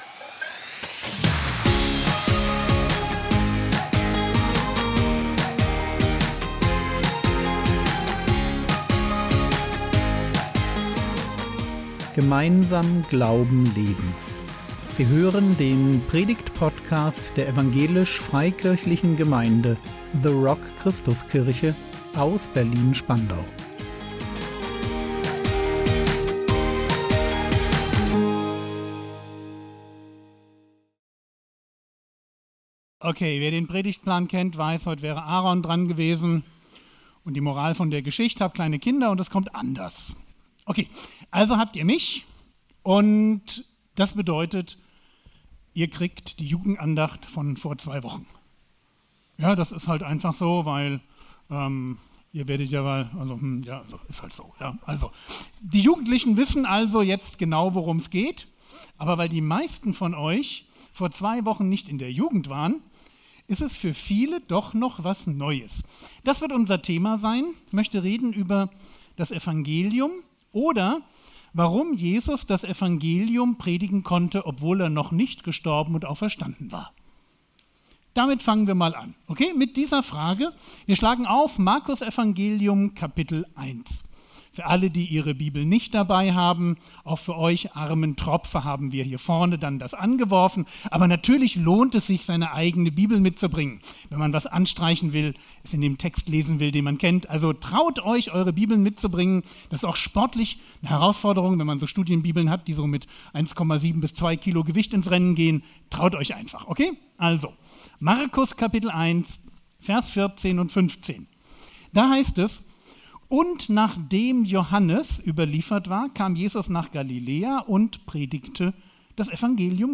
Impuls